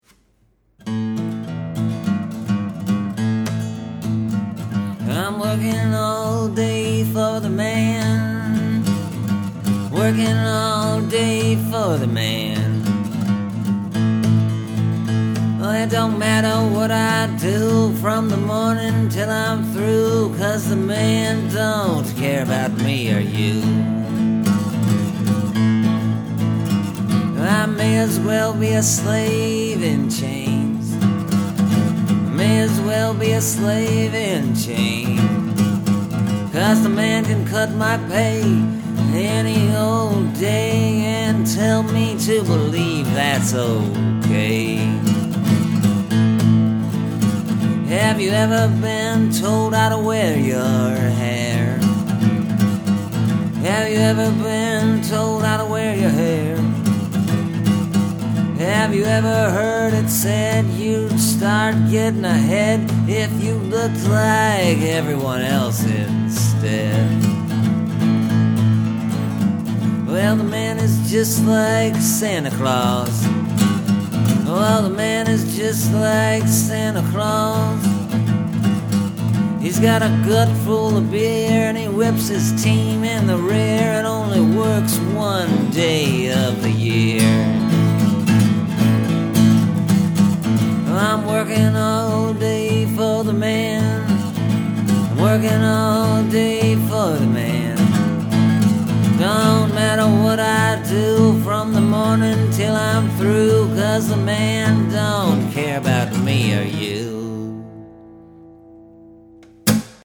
It’s sort of a blues song.